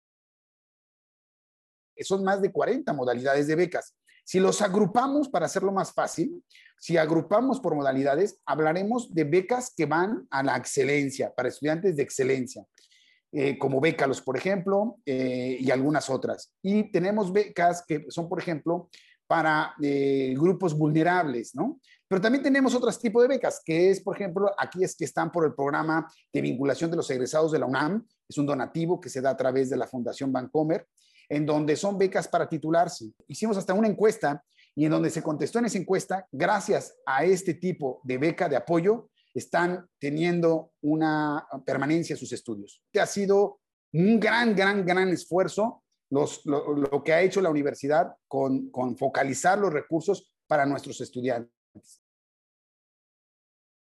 “¡Imagínate!, un gran número que no habíamos llegado a él, porque ha sido un gran esfuerzo que ha hecho la Universidad al focalizar los recursos para sus estudiantes”, aseveró en entrevista.